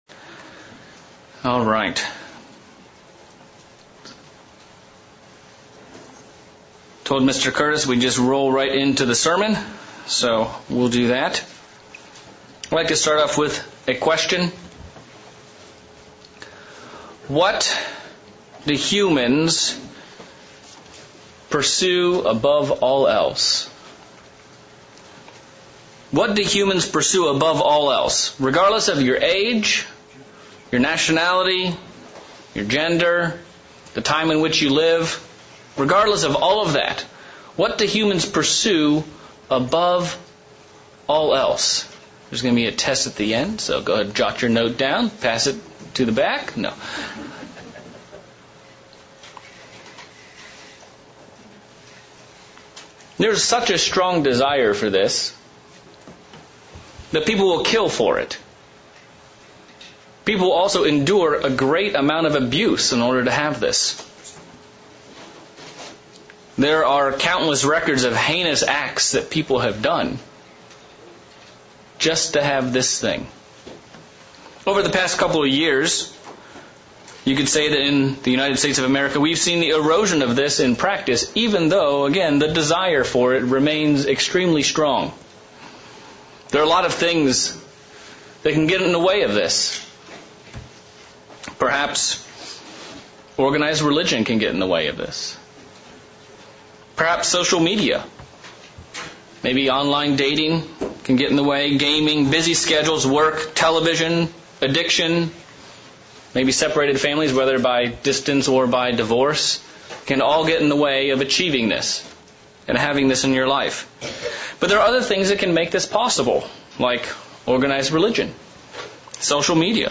Sermon looking at how we can create and maintain the proper Godly connections with our Lord and Savior.